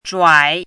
zhuǎi
zhuǎi.mp3